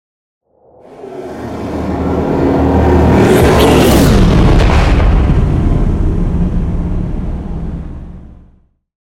Dramatic electronic whoosh to hit trailer
Sound Effects
Atonal
dark
driving
futuristic
intense
tension
woosh to hit